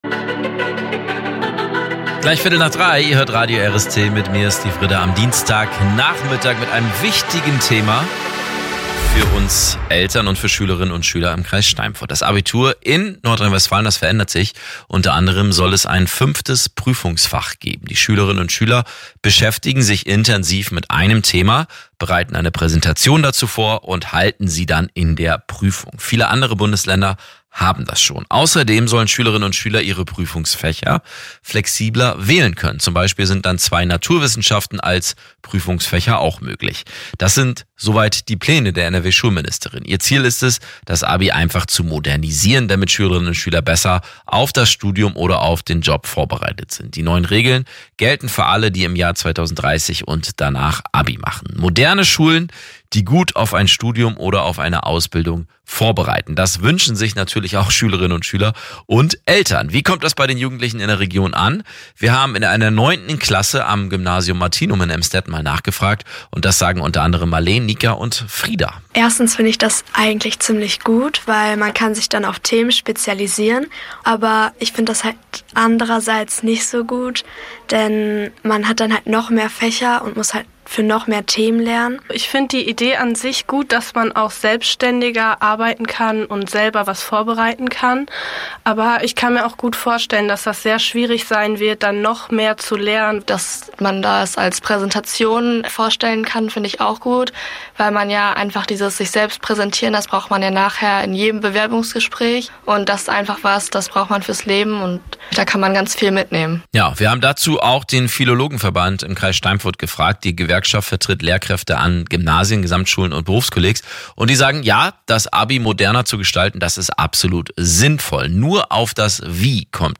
RadioRST berichtet über das Thema am Mittwoch, 28. Januar 2026.